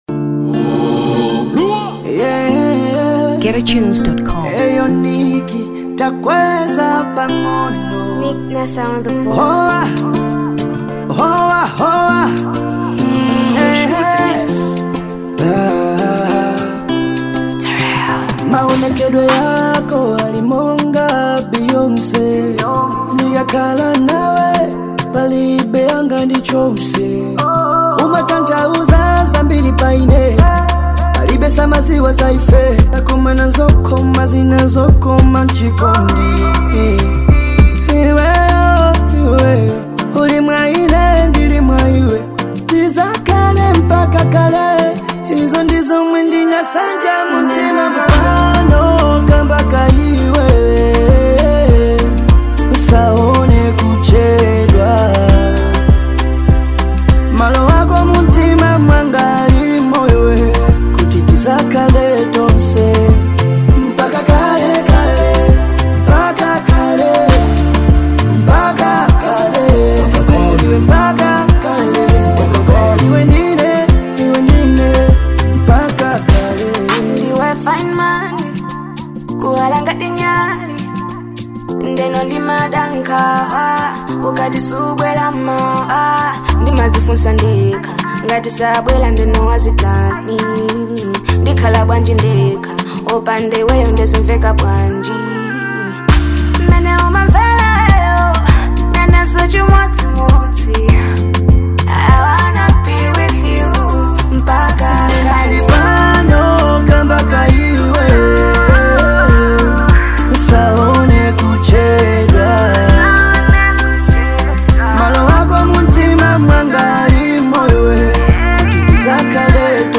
Afro Pop 2023 Malawi